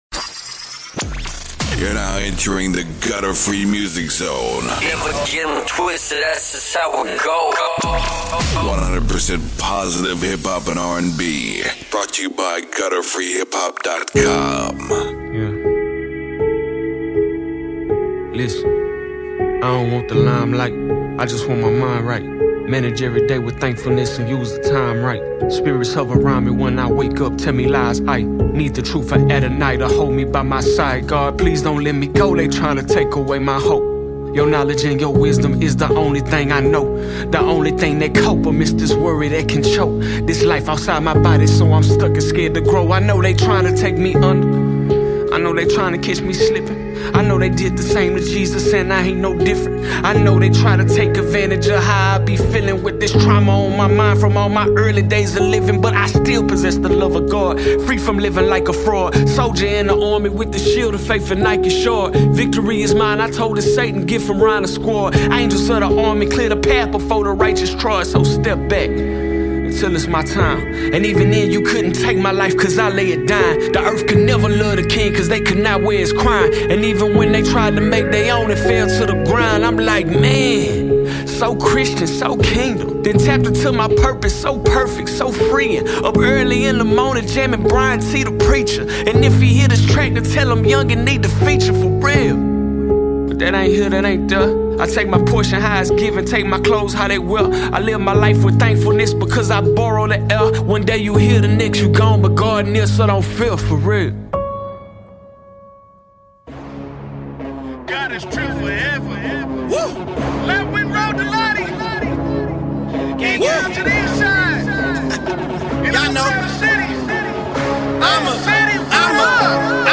Gospel Rap Mix